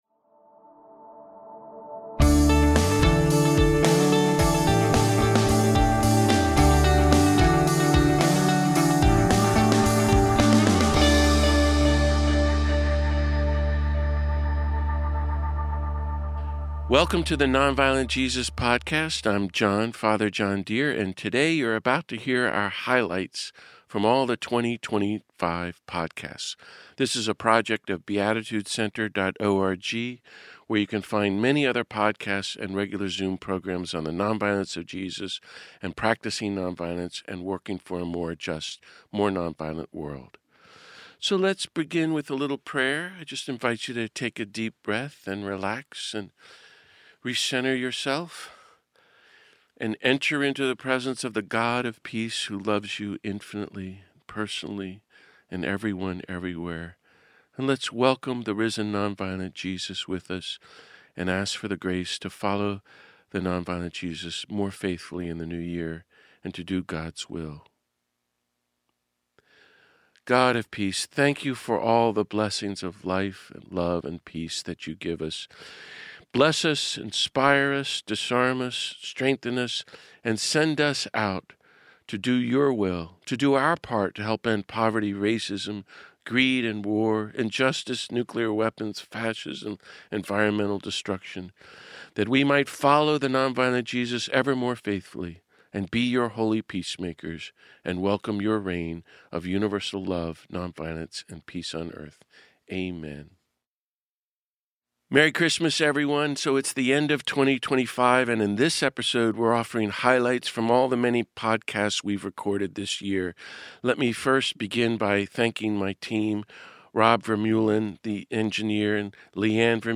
It is an astonishing collection of visionaries, teachers and peacemakers.